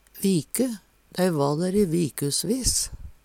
viku - Numedalsmål (en-US)